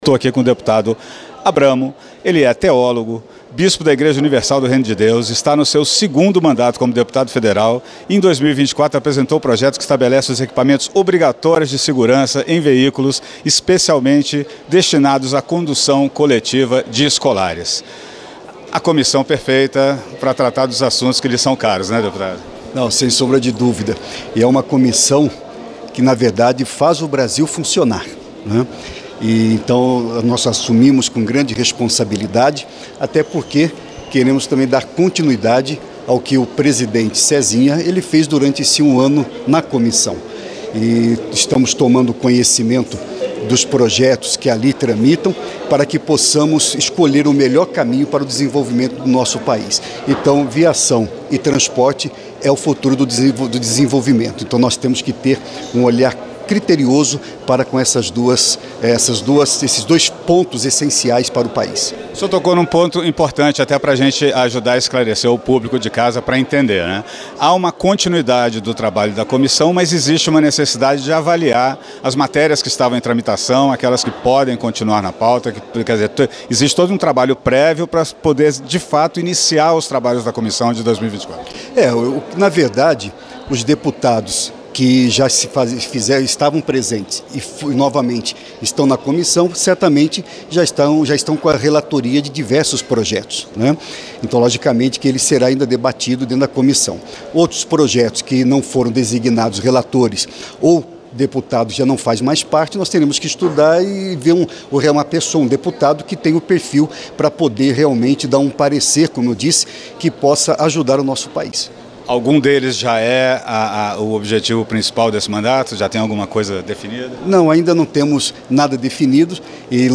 O deputado Gilberto Abramo (Republicanos-MG) conversou com a equipe da Rádio e TV Câmara logo após ter sido eleito, nesta quarta-feira (6), para comandar a Comissão de Viação e Transportes da Câmara. O parlamentar falou sobre a importância do diálogo entre Executivo e Legislativo.
entrevista-dep-gilberto-abramo.mp3